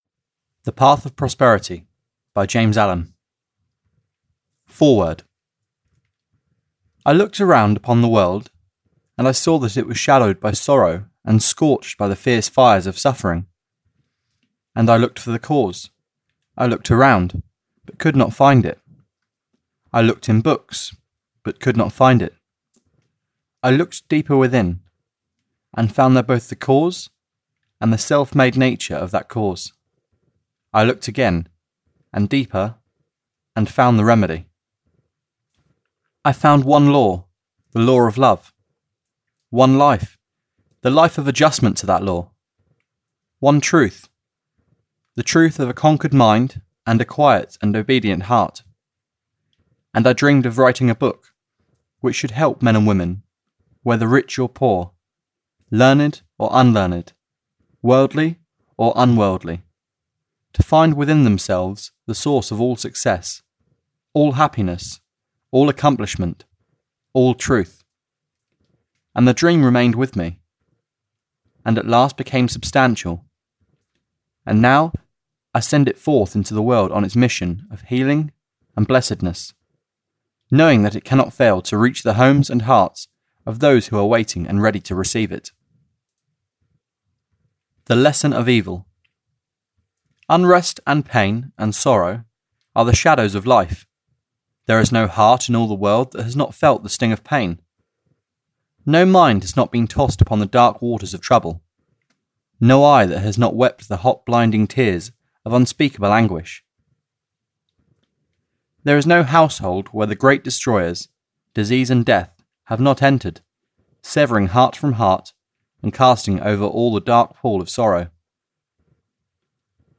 The Path Of Prosperity (EN) audiokniha
Ukázka z knihy